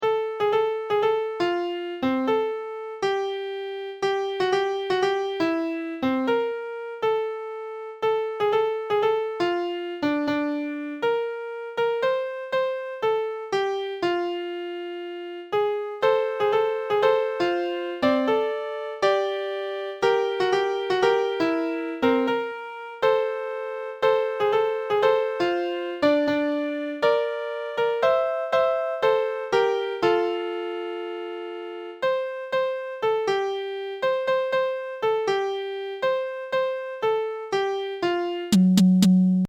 Full, tunes together (one time through - not adding "you" and "we" parts), with ending: